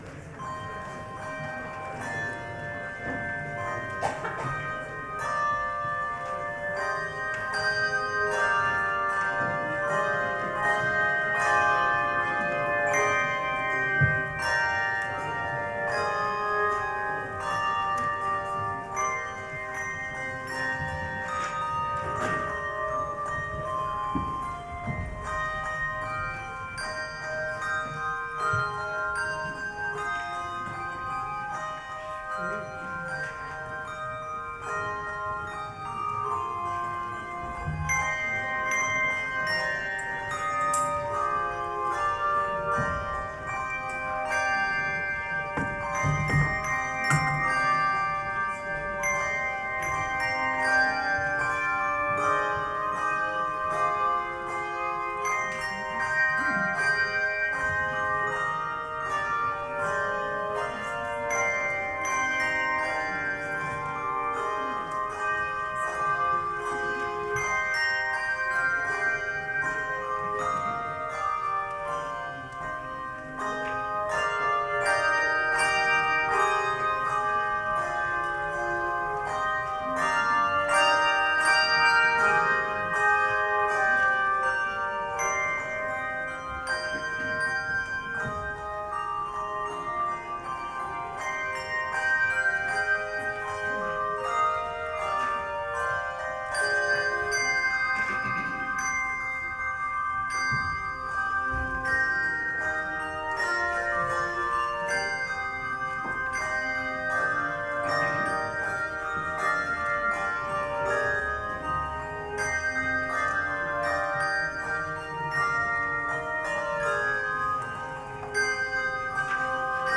by Derek K. Hakes
By Cathy Mocklebust
Performed by The FMC Hand Bell Choir
Carol+Sing+25+-+Hand+Bells(2).wav